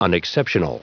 Prononciation du mot unexceptional en anglais (fichier audio)
Prononciation du mot : unexceptional